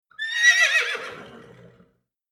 Download Horse sound effect for free.
Horse